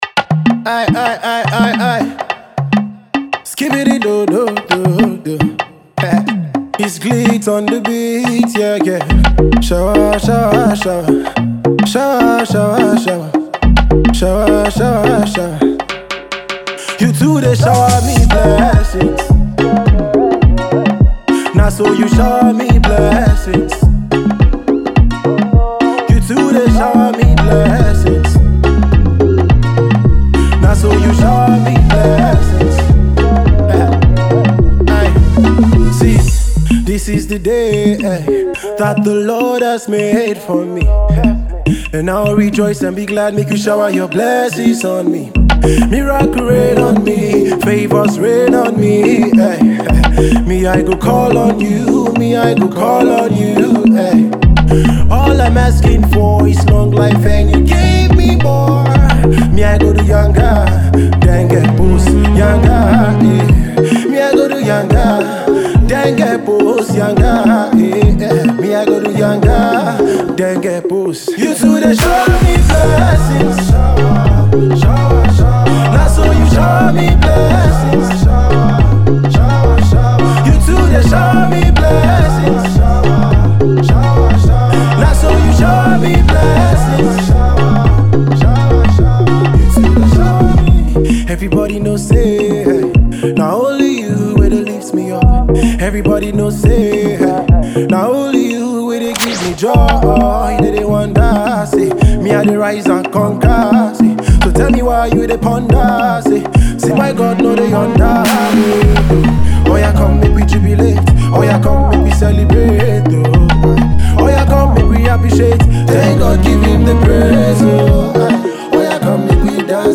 Gospel minister
captivating and soul uplifting piece